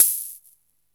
Closed Hats
Wu-RZA-Hat 67.wav